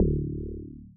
timeout_tick.wav